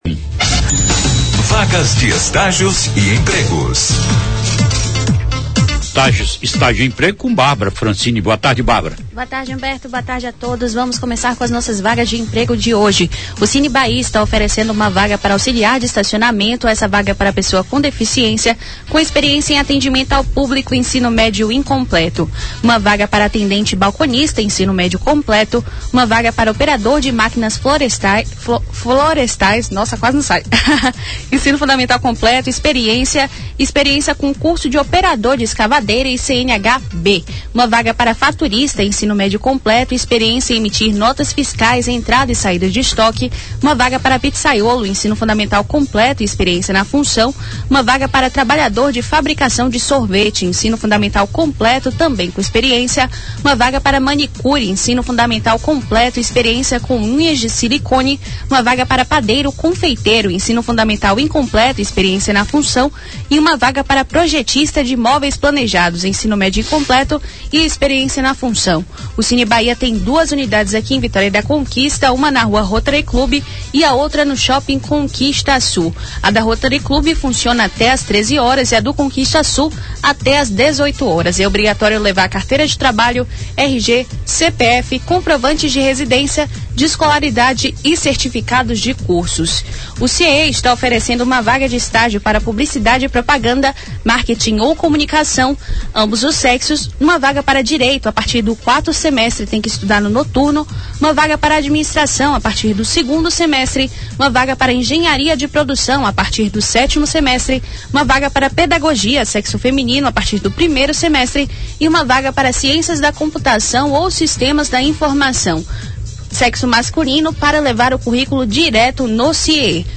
Na sonora, a jovem traz todos os destaques de empregos e estágios em Vitória da Conquista.